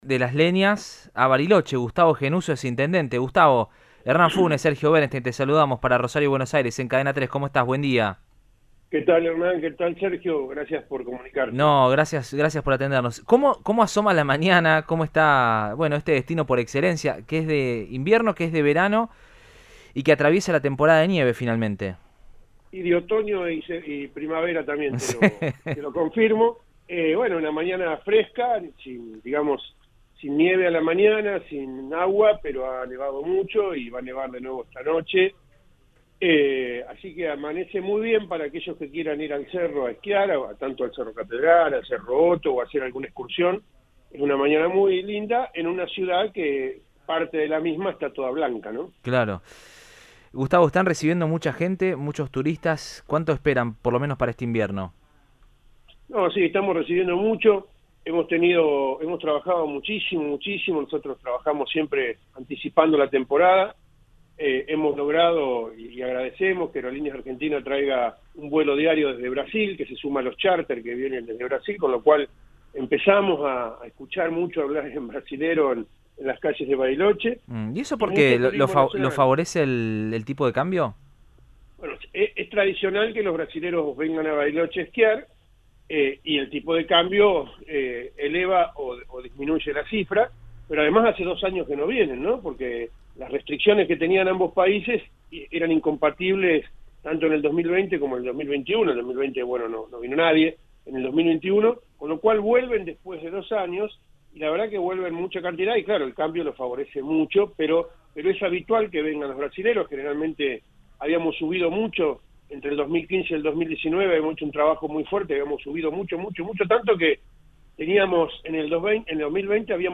Gustavo Gennuso, intendente de Bariloche brindó detalles de la temporada invernal y cómo está cambiando la ciudad de cara a una mayor población nativa.
Durante el fin de semana nevó mucho en Bariloche para que los turistas puedan ir a los cerros a esquiar, “hoy la ciudad está toda blanca” dijo el mandatario municipal en diálogo con Radioinforme 3 de Cadena 3 Rosario.